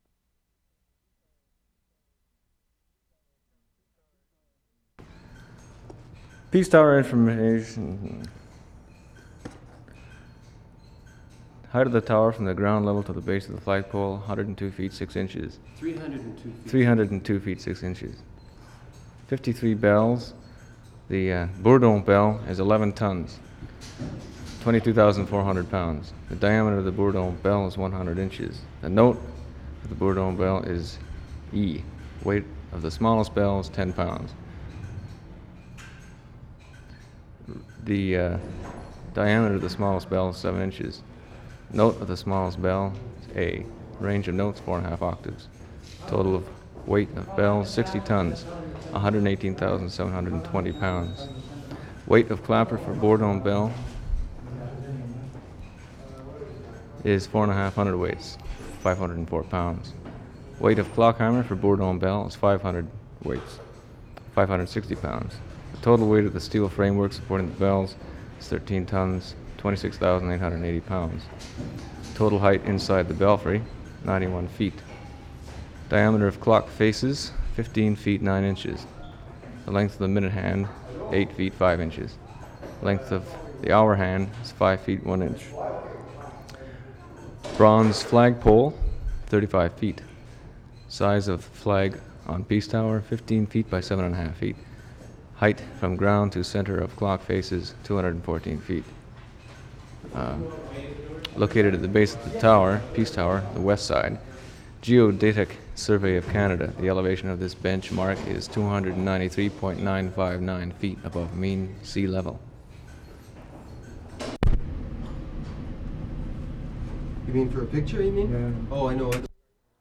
OTTAWA, ONTARIO Oct. 29, 1973
3. Information on chimes (size, weight, etc.) read by recordist from plaque on wall of the clock room. Throughout take, ticking in the background.